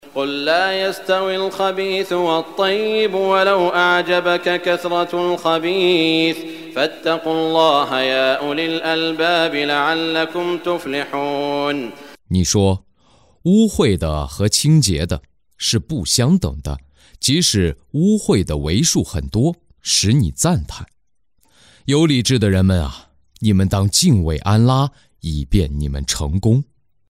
中文语音诵读的《古兰经》第（玛仪戴）章经文译解（按节分段），并附有诵经家沙特·舒拉伊姆的朗诵